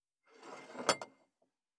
396,机の上をスライドさせる,スー,ツー,サッ,
効果音